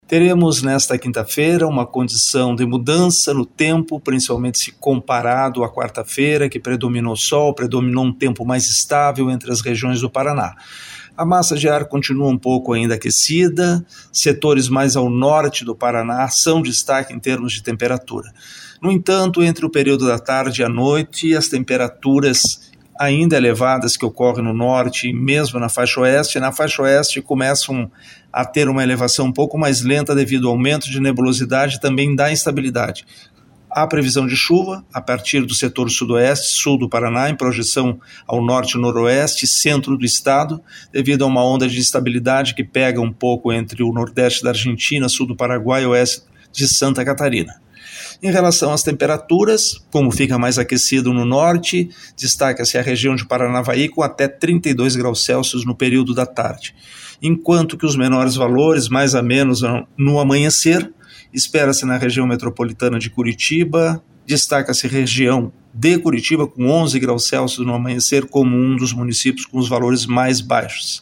Ouça os detalhes com o meteorologista do Simepar